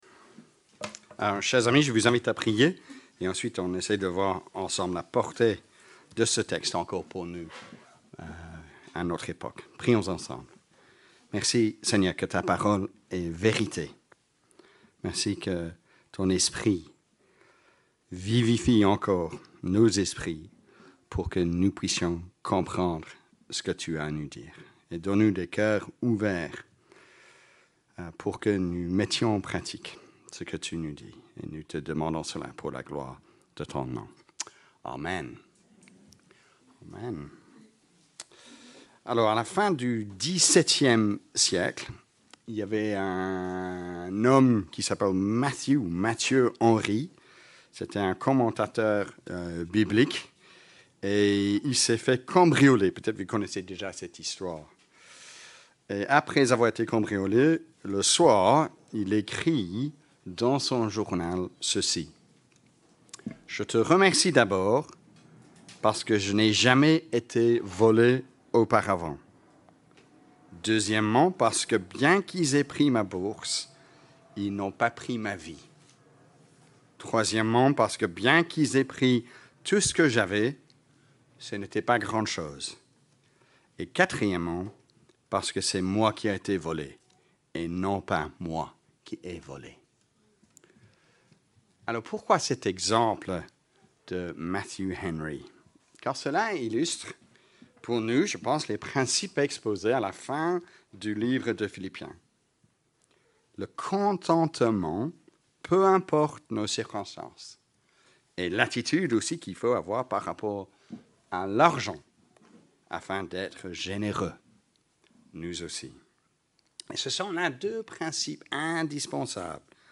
prédication-du-culte-du-9-juillet-2023.mp3